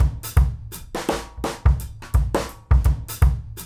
GROOVE 210KL.wav